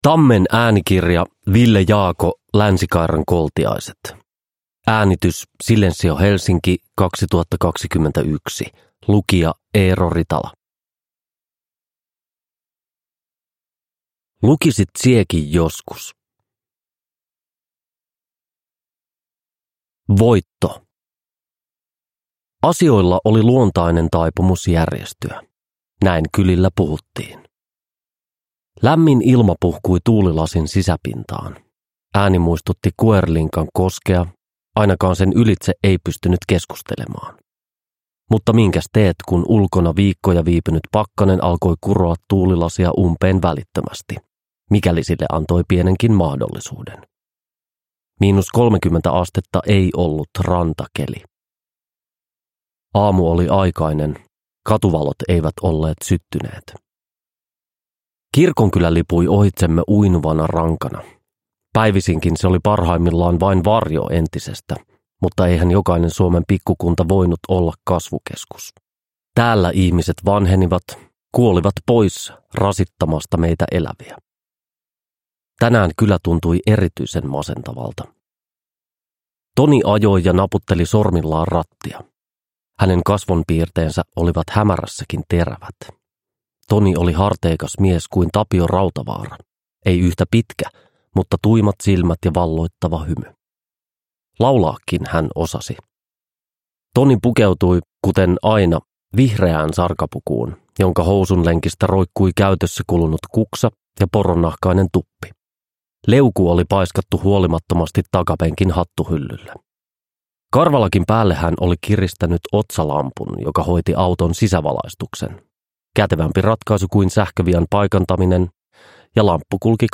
Uppläsare: Eero Ritala